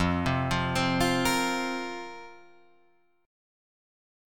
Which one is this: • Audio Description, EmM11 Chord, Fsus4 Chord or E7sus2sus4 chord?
Fsus4 Chord